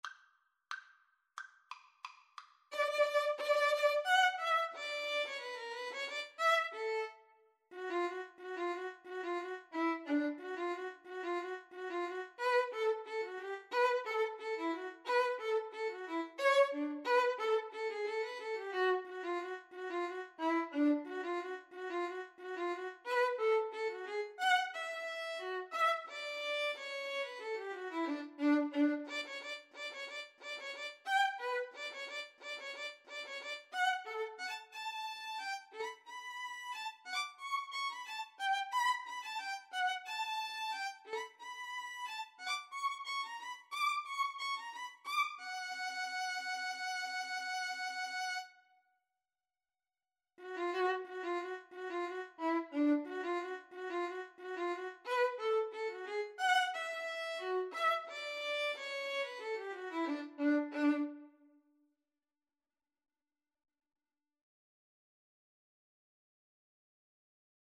Allegro =180 (View more music marked Allegro)
Classical (View more Classical Violin-Cello Duet Music)